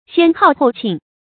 先號后慶 注音： ㄒㄧㄢ ㄏㄠˋ ㄏㄡˋ ㄑㄧㄥˋ 讀音讀法： 意思解釋： 謂初兇后吉。